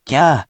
We’re going to show you the character(s), then you you can click the play button to hear QUIZBO™ sound it out for you.
In romaji, 「きゃ」 is transliterated as「kya」which sounds like 「kyah」
The most important thing to learn this lesson is that this is not pronounced ‘KEE-YAH’, it’s simply ‘KYAH’, one syllable.